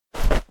dive_roll_1.ogg